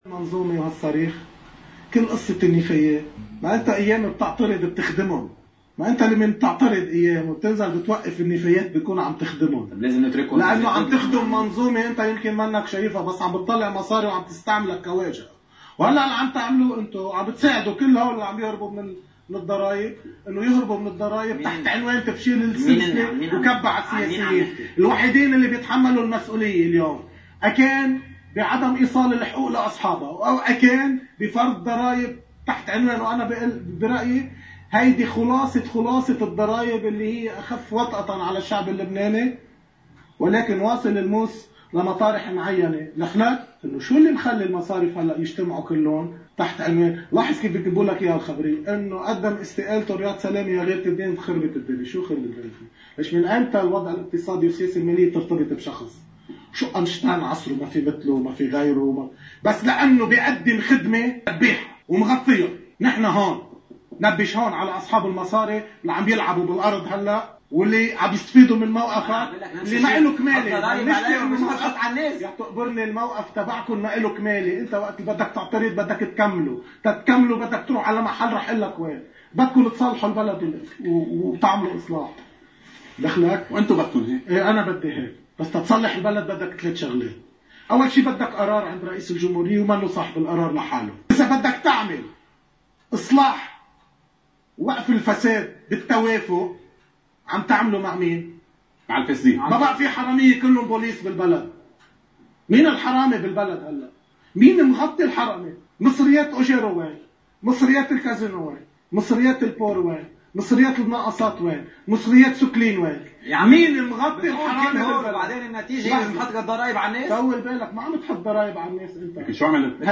ضمن برنامج “حديث الساعة” عَ قناة المنار